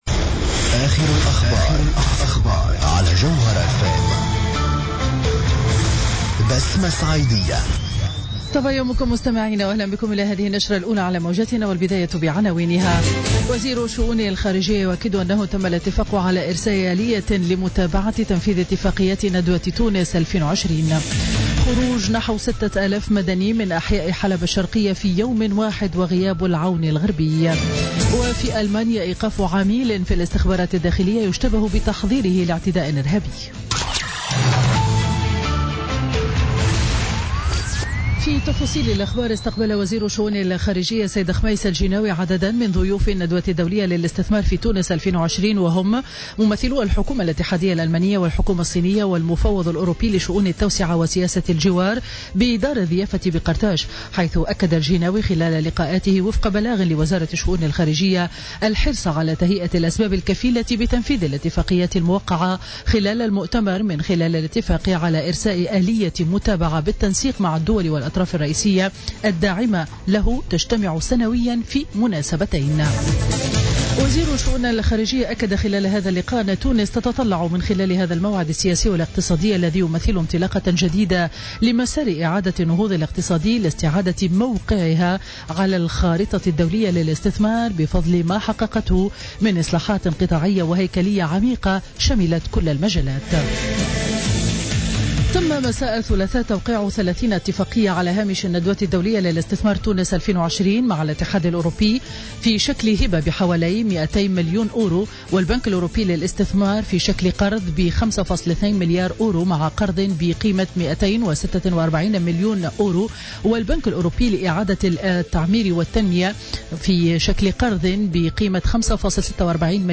نشرة أخبار السابعة صباحا ليوم الأربعاء 30 نوفمبر 2016